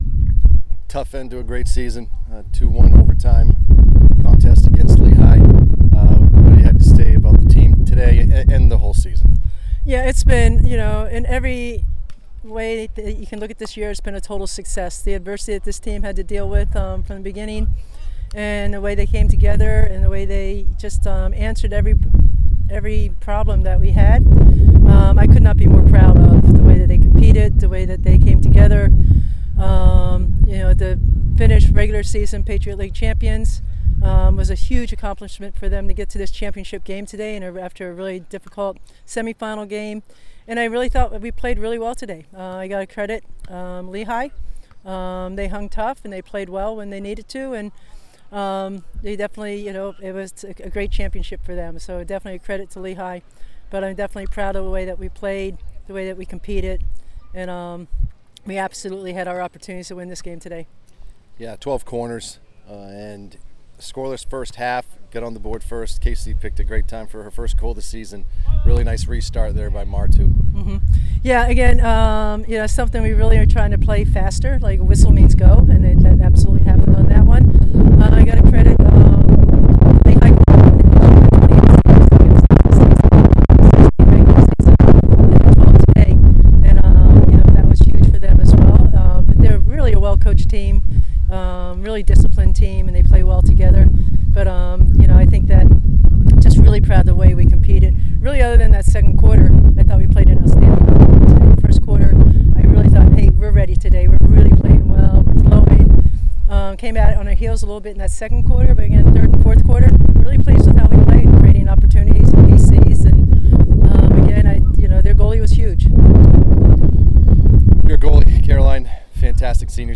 Lehigh Postgame Interview